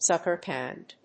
ツカーカンドル； ツッケルカンドル